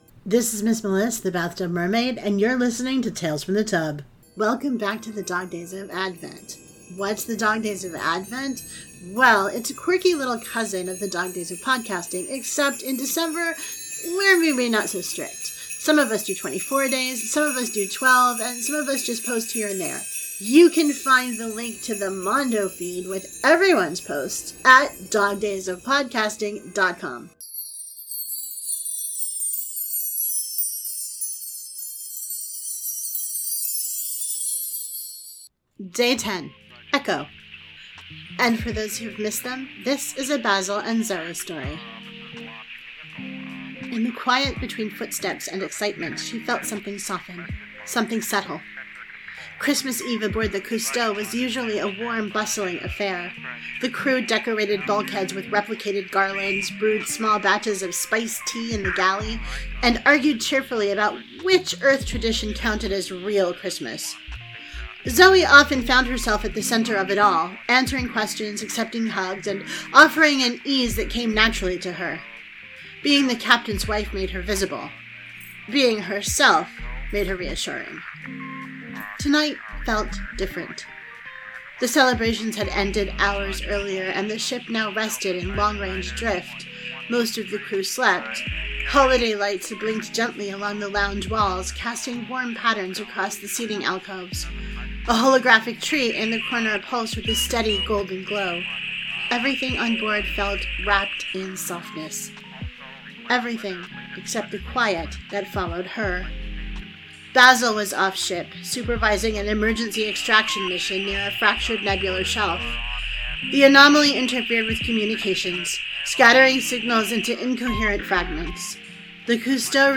• Sound Effects and Music are from Freesound and UppBeat